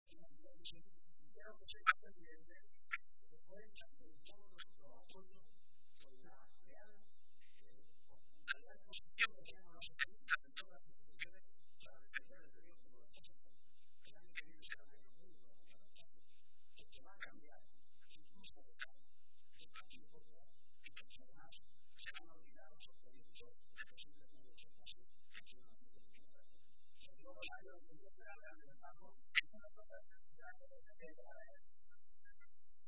A la tradicional comida navideña de los socialistas toledanos asistieron alrededor de 1.800 personas.